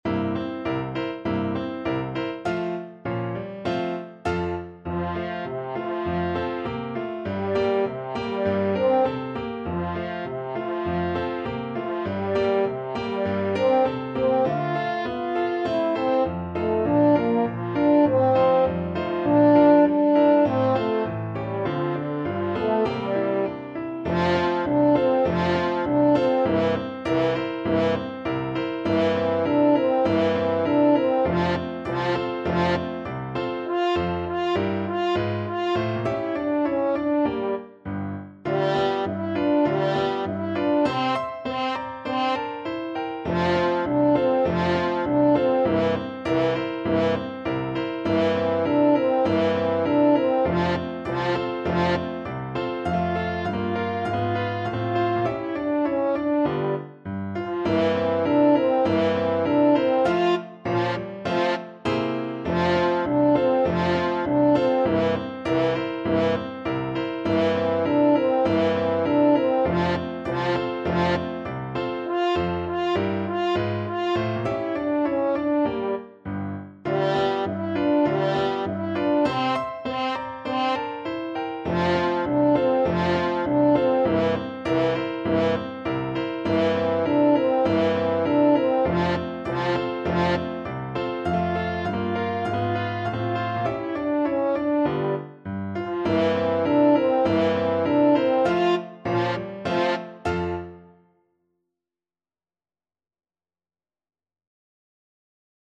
French Horn version
2/2 (View more 2/2 Music)
Moderato =c.100